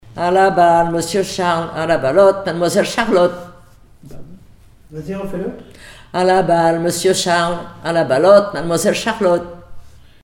enfantine : jeu de balle
comptines et formulettes enfantines
Pièce musicale inédite